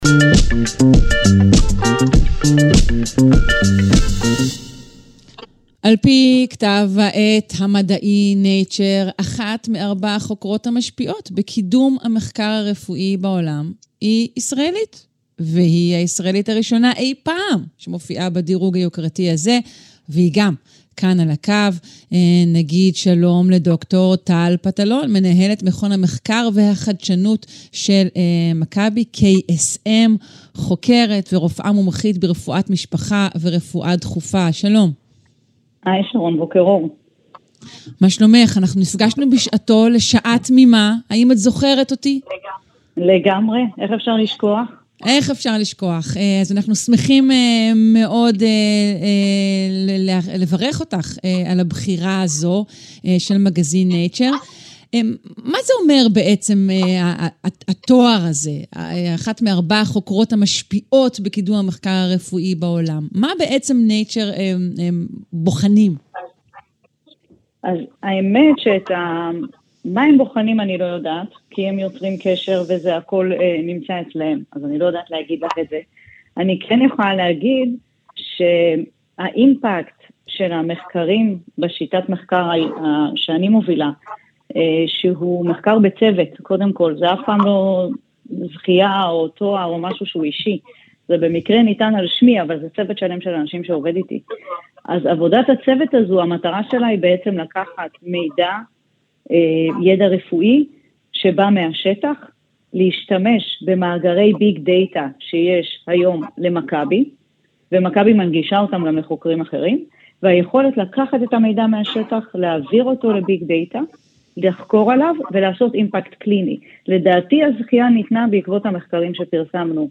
An Interview